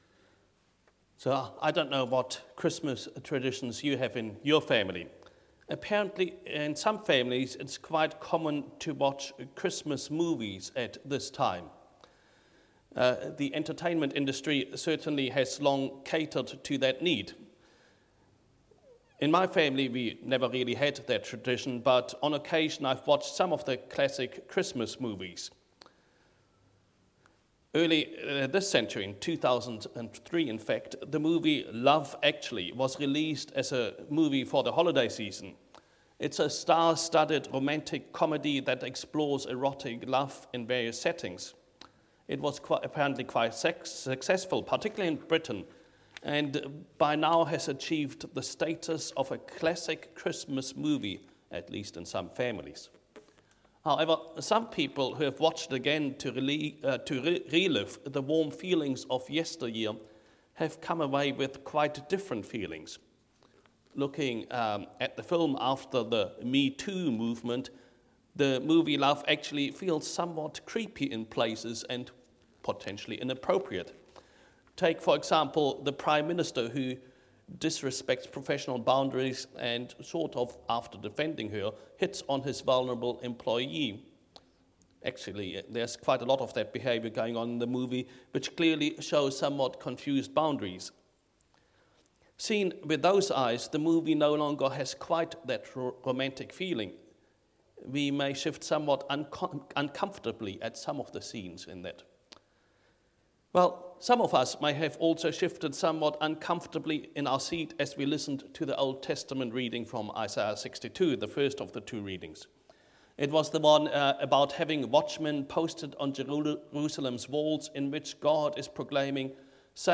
A sermon for Christmas Eve.